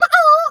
chicken_2_bwak_01.wav